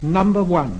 This is Jones producing his secondary cardinal 6, [ʌ]: